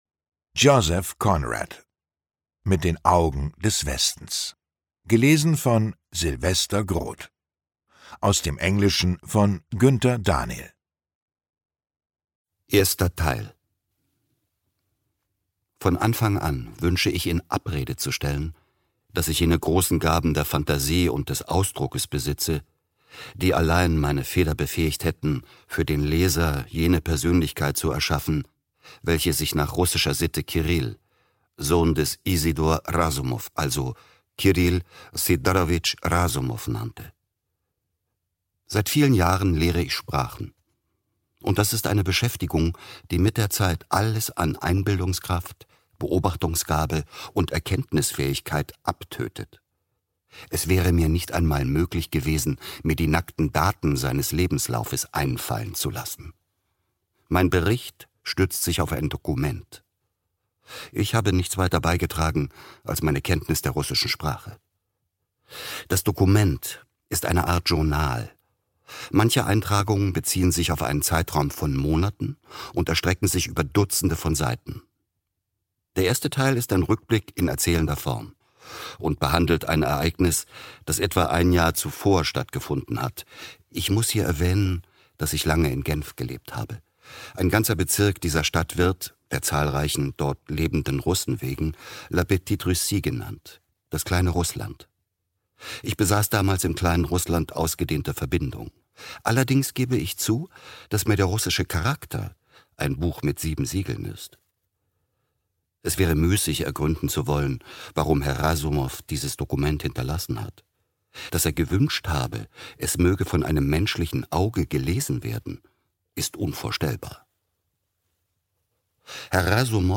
Lesung mit Sylvester Groth (1 mp3-CD)
Sylvester Groth (Sprecher)
»Hier wird fündig, wer an Hörbuchproduktionen Freude hat, die nicht schnell hingeschludert sind, sondern mit einer Regie-Idee zum Text vom und für den Rundfunk produziert sind.« NDR KULTUR